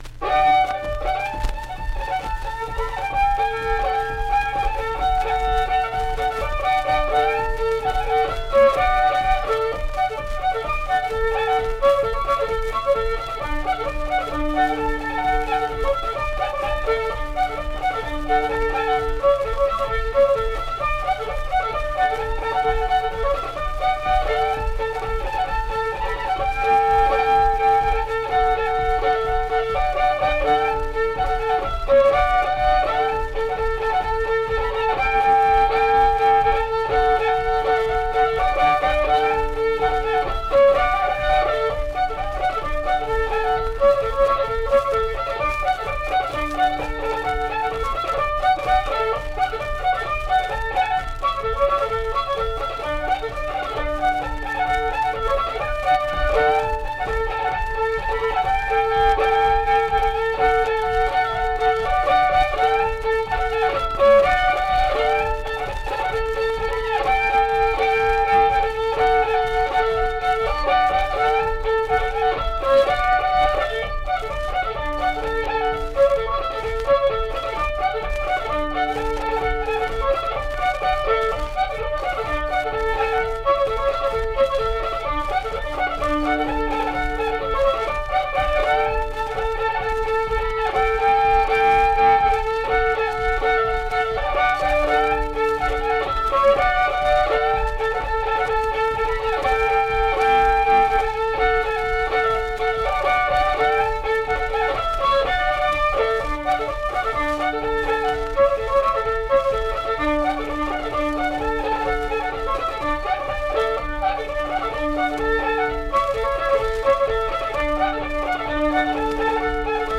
Unaccompanied fiddle music
in Beverly, WV.
Instrumental Music
Fiddle
Randolph County (W. Va.)